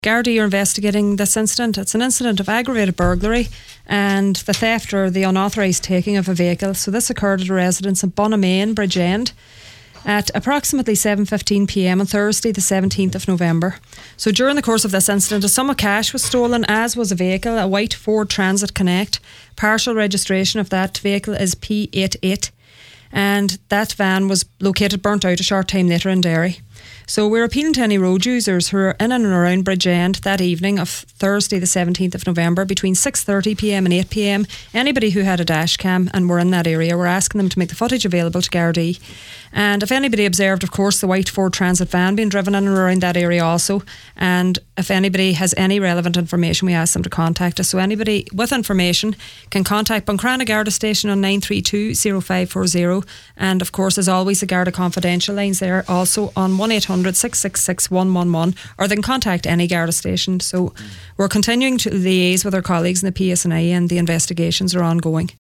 made this appeal on today’s Nine til Noon Show……..